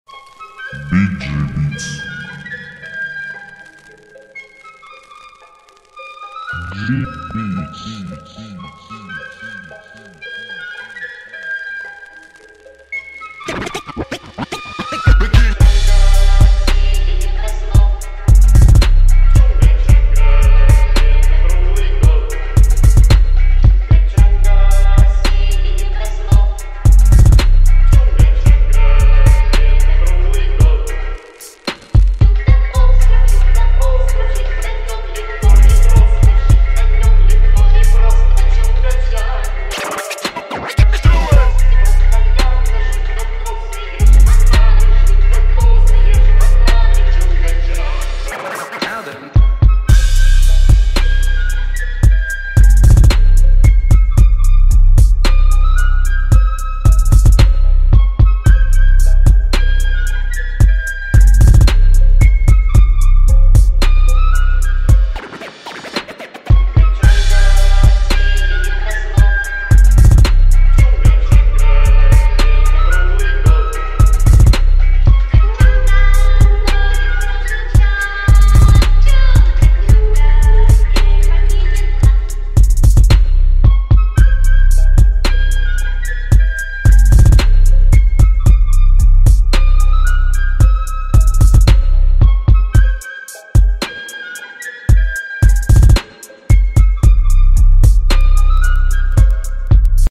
Trap remix twist